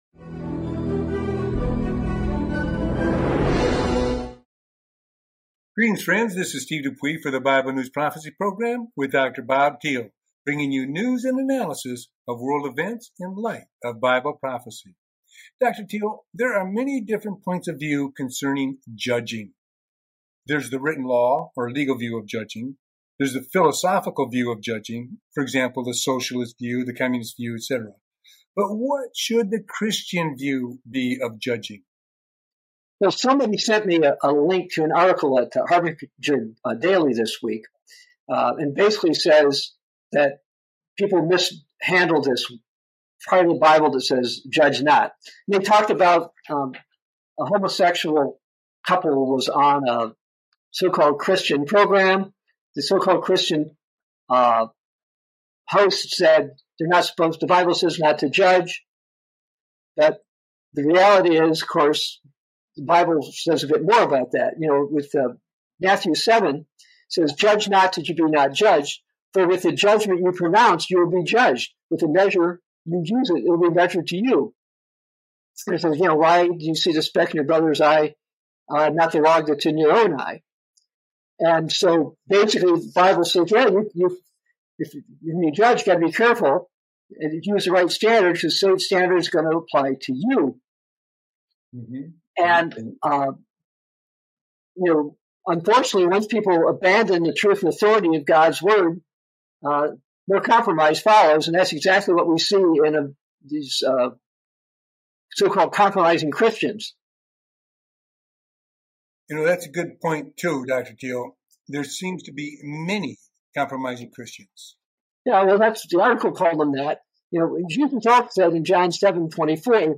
Bible News Prophecy Talk Show